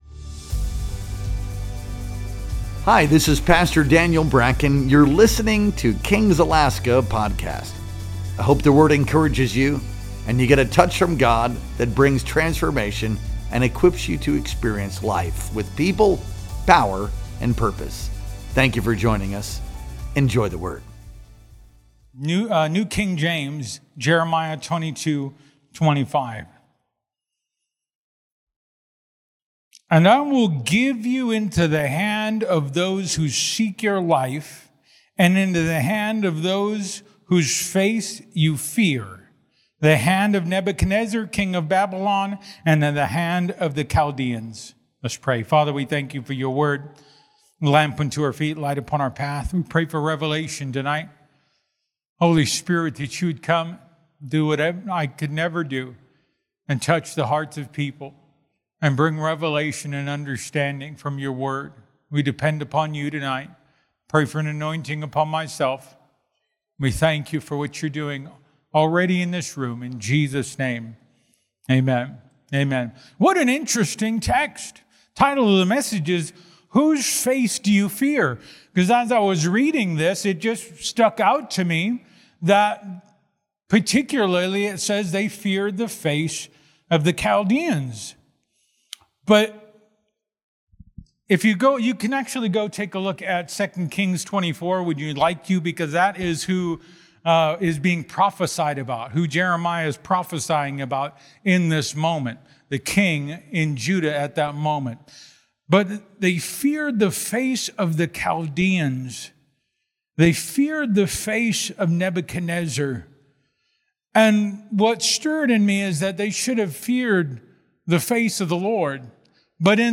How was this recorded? Our Wednesday Night Worship Experience streamed live on November 19th, 2025.